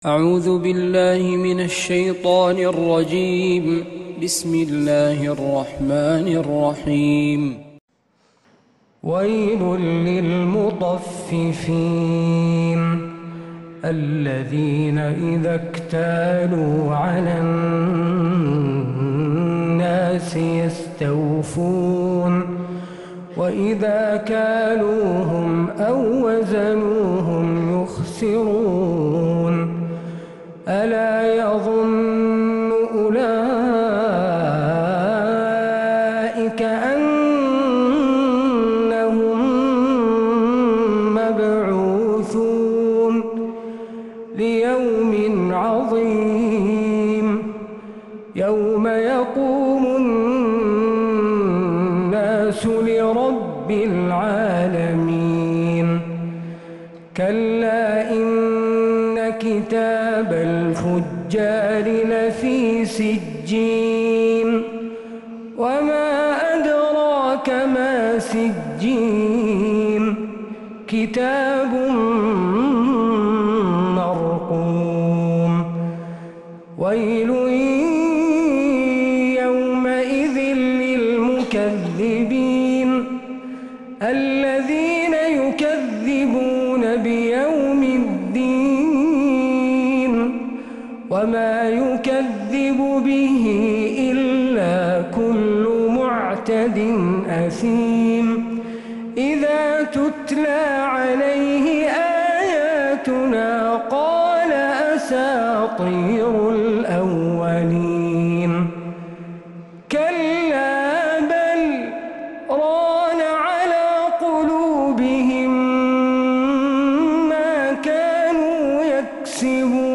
سورة المطففين كاملة من عشائيات الحرم النبوي